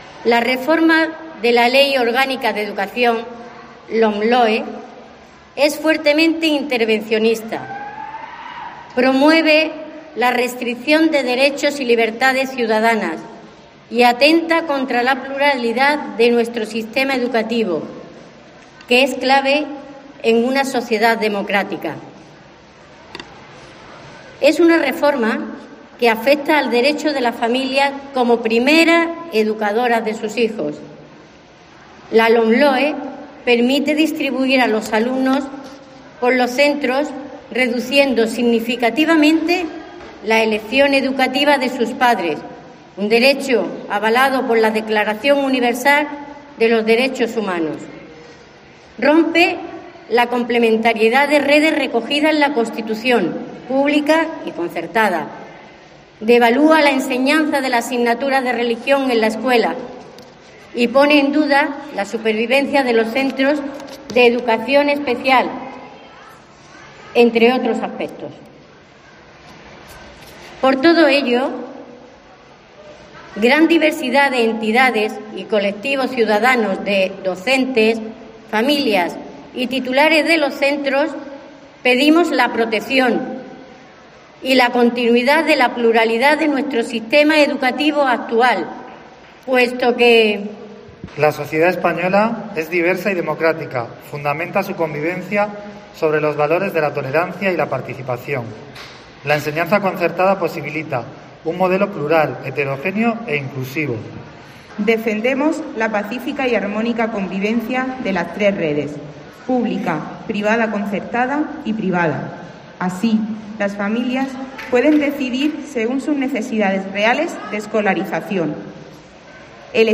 Lectura manifiesto Madres Mercedarias